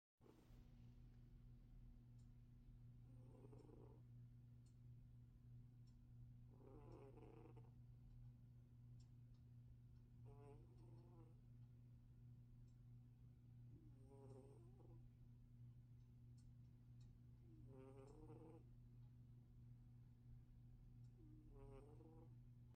My cat is snoring.
I Mp3 Sound Effect My cat is snoring. I hope you can hear it.